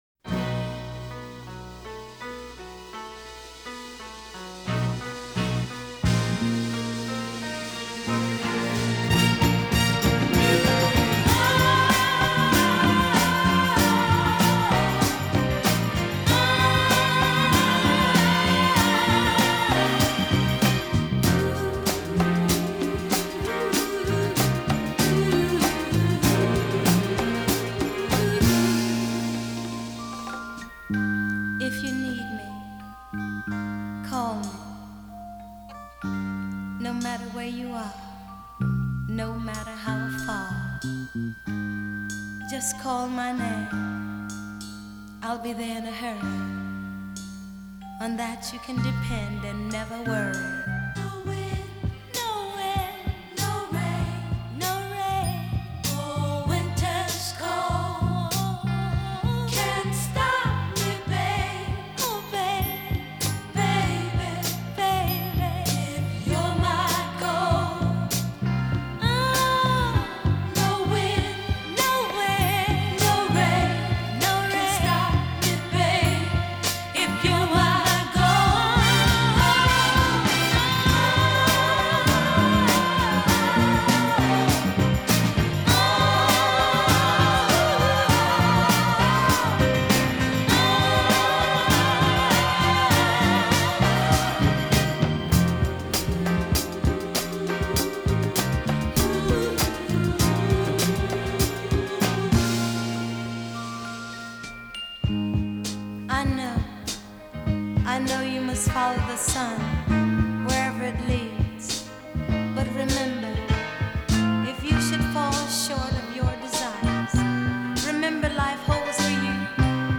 This is the mono single edit.